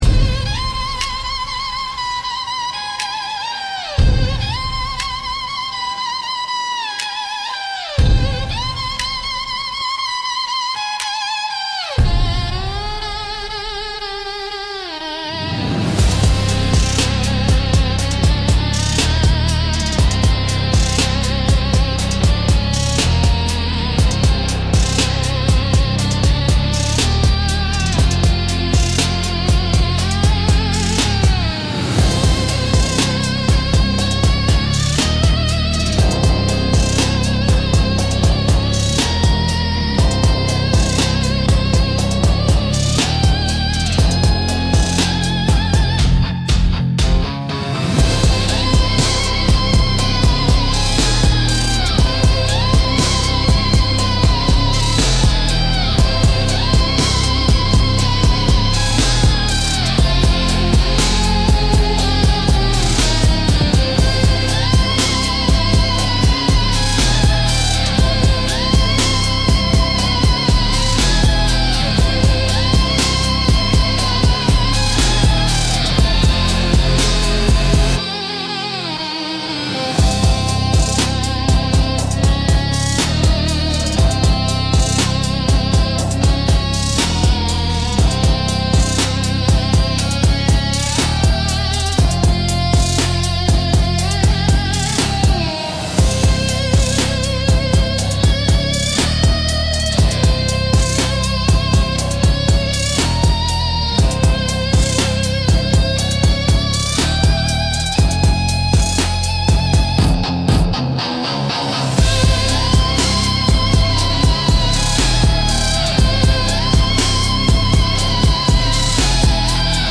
Instrumental - Unbuttoned Questions